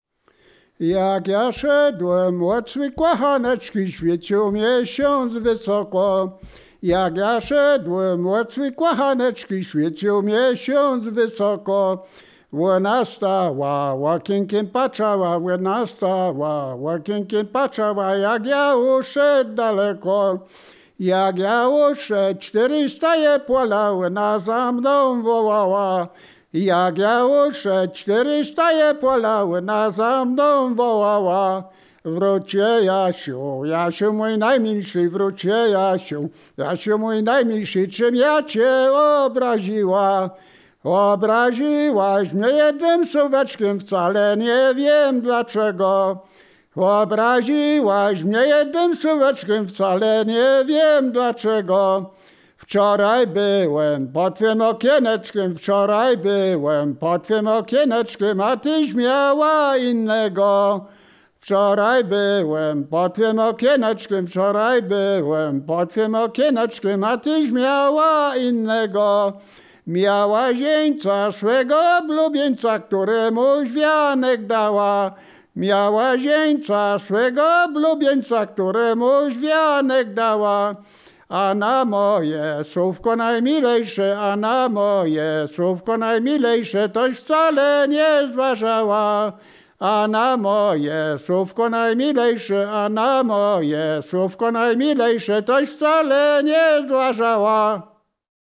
liryczne miłosne